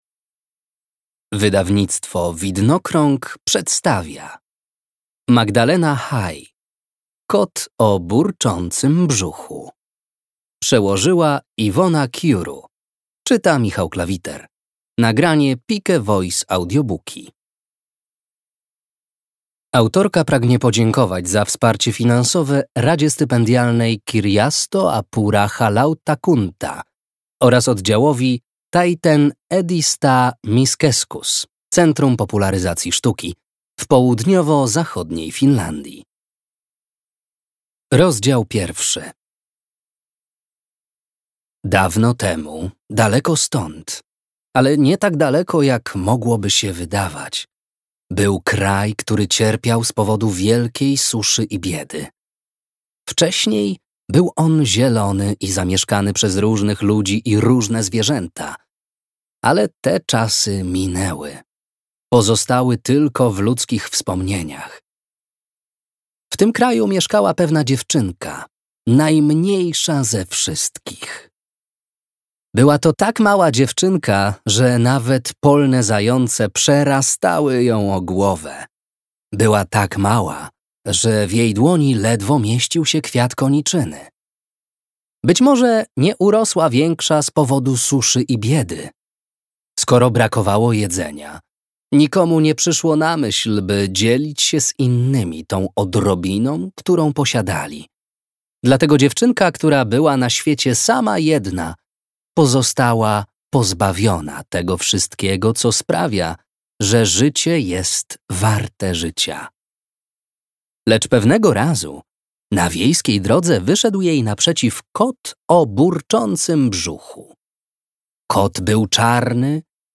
Audiobook + książka Kot o burczącym brzuchu, Hai Magdalena.